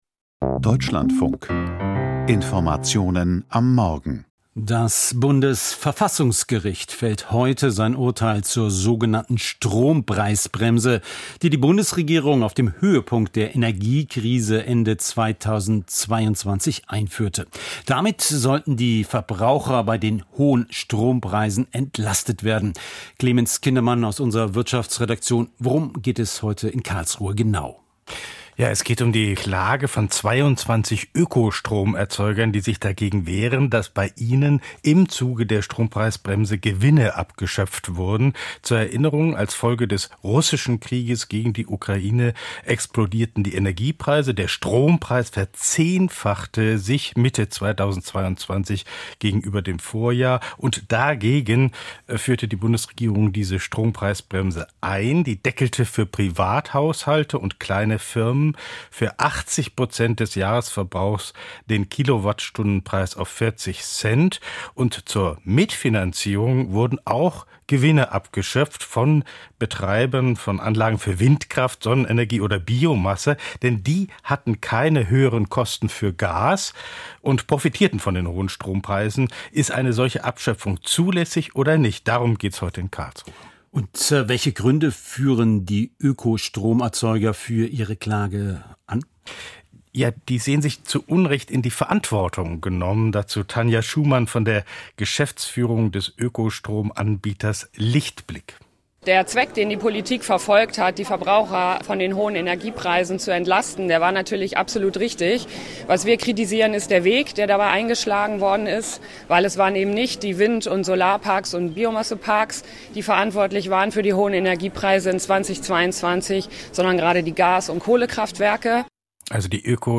Wirtschaftsgespräch: Bundesverfassungsgericht urteilt zur Strompreisbremse